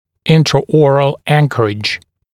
[ˌɪntrə»ɔːrəl ‘æŋkərɪʤ][ˌинтрэ’о:рэл ‘энкэридж]внутриротовая опора